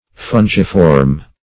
Search Result for " fungiform" : The Collaborative International Dictionary of English v.0.48: Fungiform \Fun"gi*form\, a. [Eungus + -form: cf. F. fongiforme.]